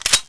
sliderelease.wav